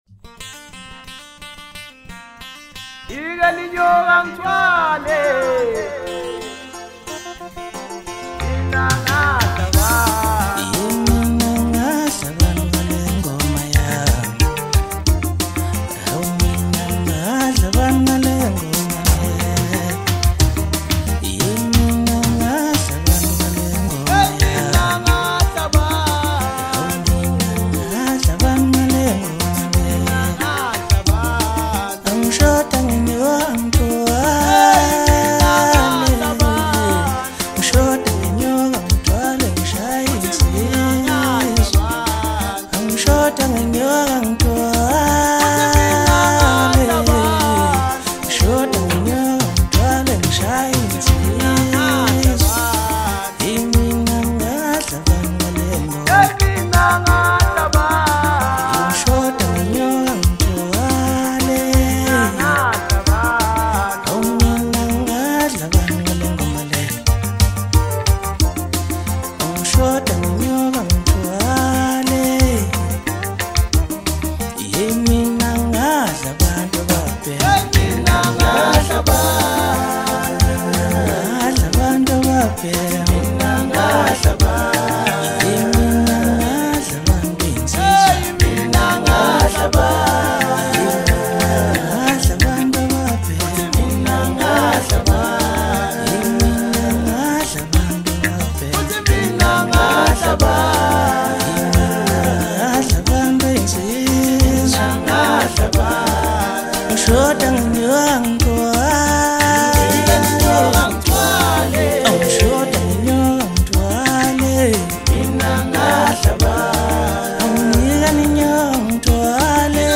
Maskandi
South African singer